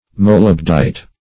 Meaning of molybdite. molybdite synonyms, pronunciation, spelling and more from Free Dictionary.
molybdite - definition of molybdite - synonyms, pronunciation, spelling from Free Dictionary Search Result for " molybdite" : The Collaborative International Dictionary of English v.0.48: Molybdite \Mo*lyb"dite\, n. (Min.)